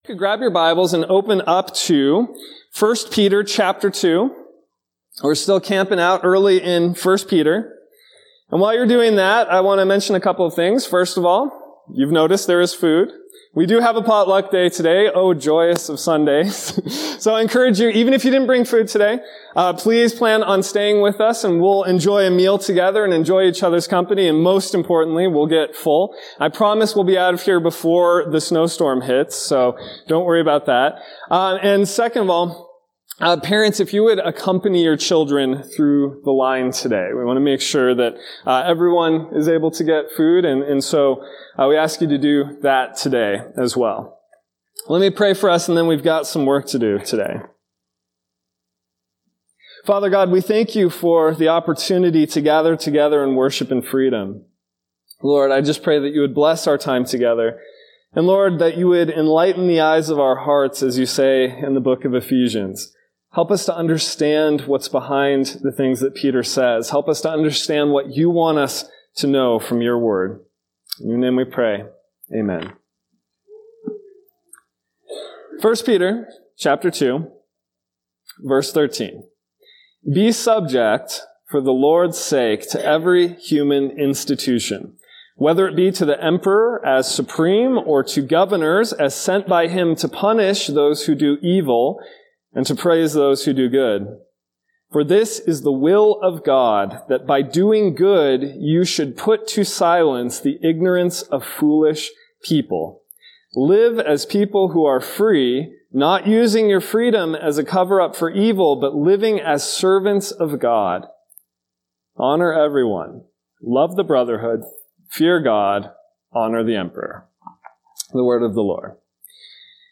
Sermons by Series | Sandy Ridge Church